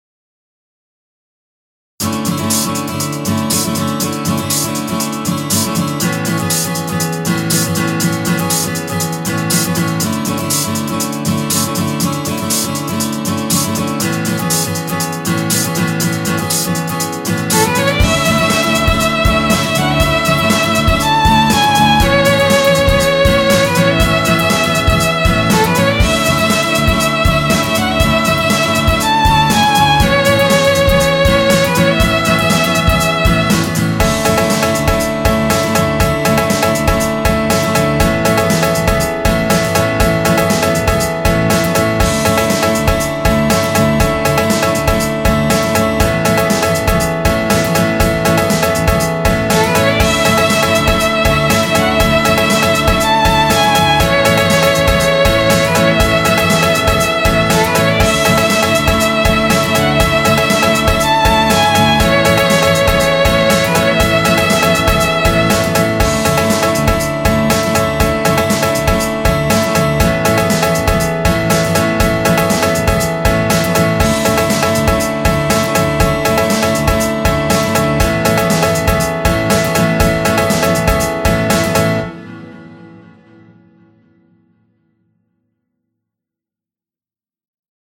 RPGの高原地帯でありそうな曲.mp3